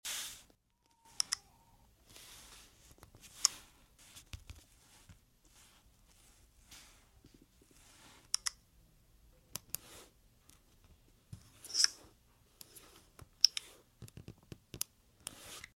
Galaxy S24 Ultra One UI sound effects free download
Galaxy S24 Ultra One UI 7.0 Sounds Clicks S-Pen